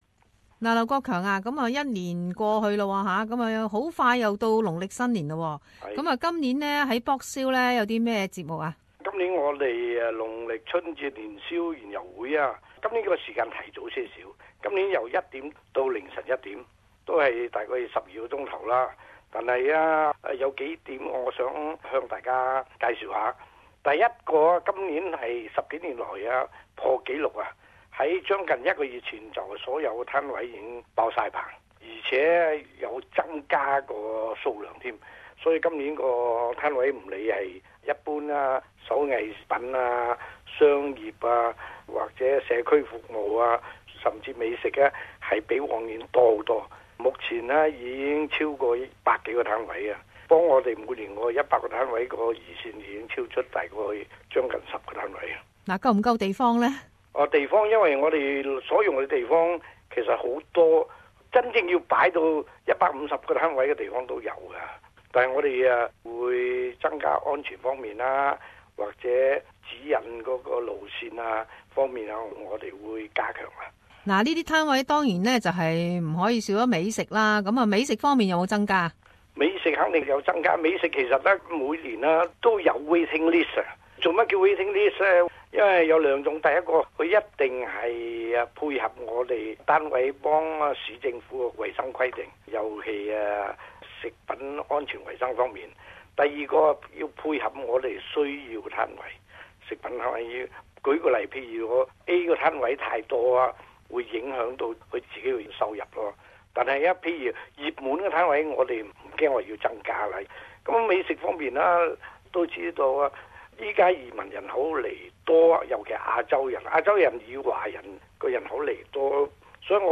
社团专访 - Box Hill 农历新年园游会